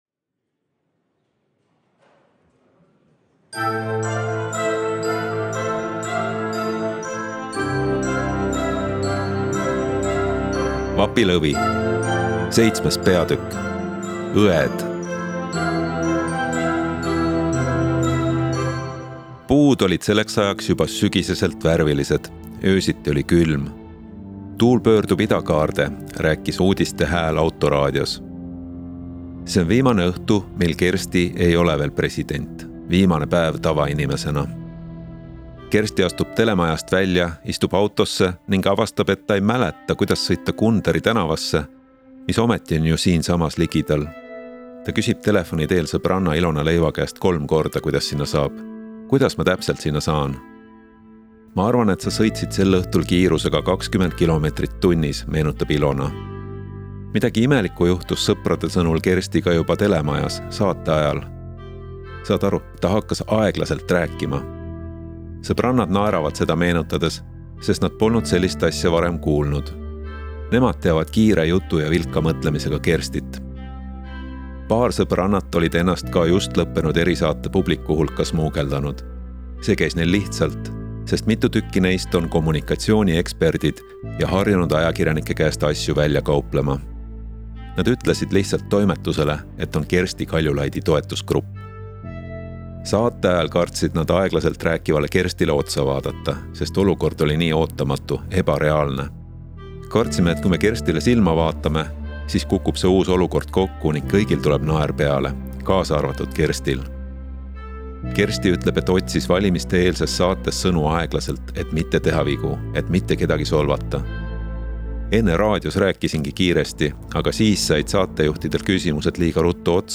Vapilõvi-audiolugu-VII-osa.mp3